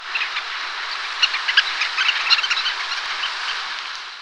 Ruddy Turnstone
Arenaria interpres
VOICE: A squeaky chatter.